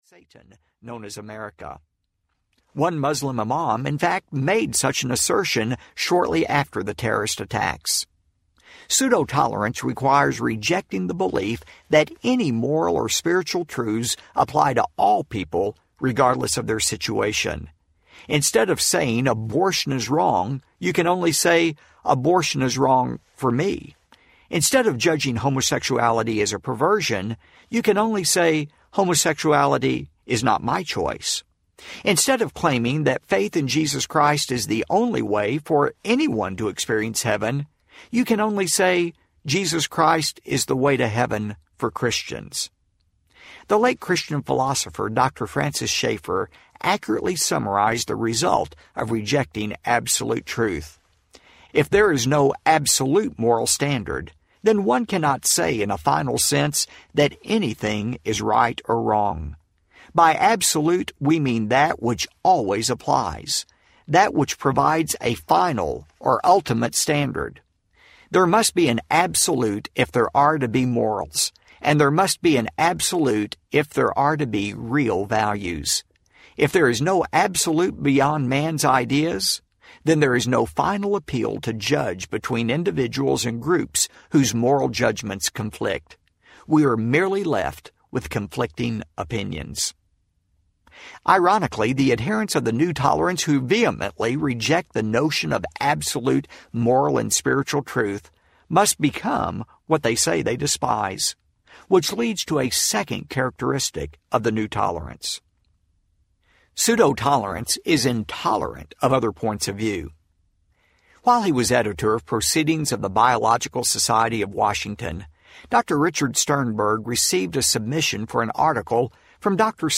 Twilight’s Last Gleaming Audiobook